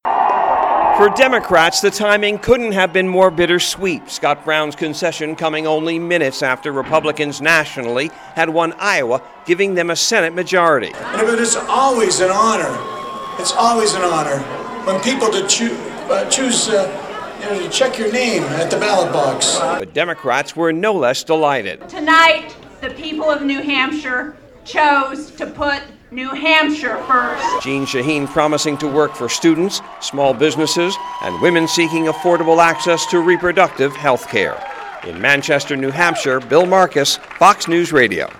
REPORTS FROM MANCHESTER, NEW HAMPSHIRE.